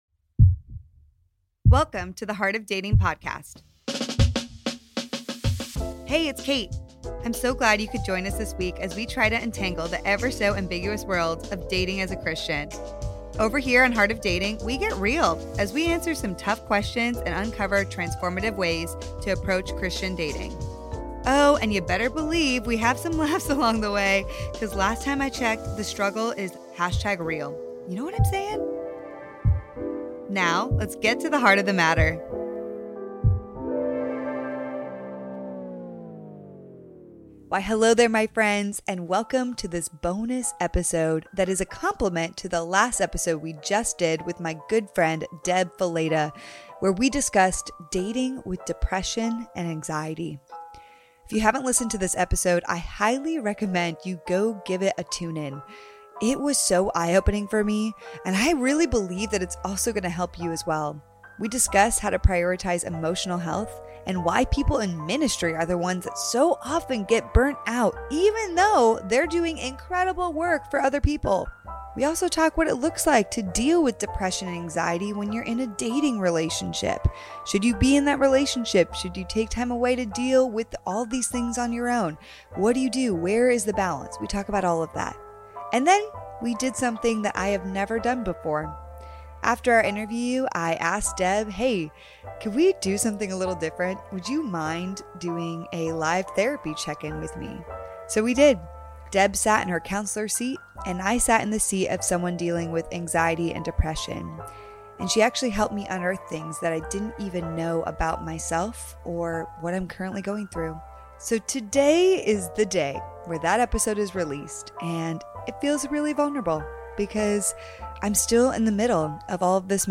MINISODE: Live Therapy Session